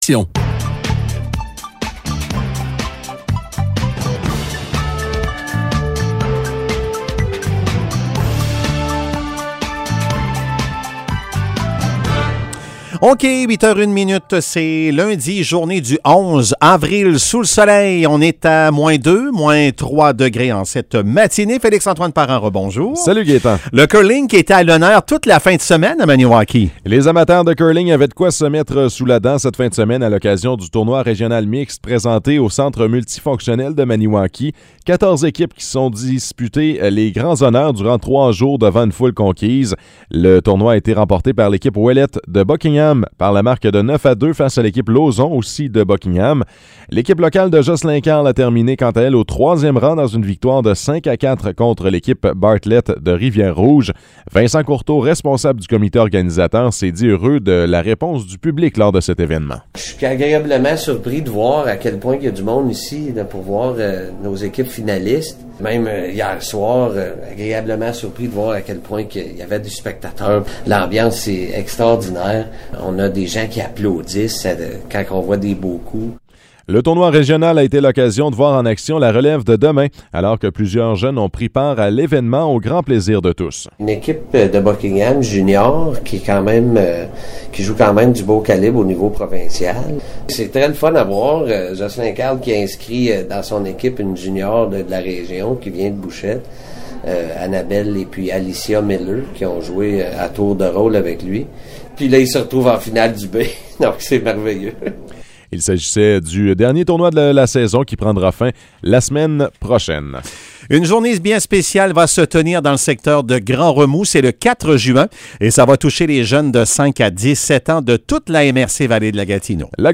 Nouvelles locales - 11 avril 2022 - 8 h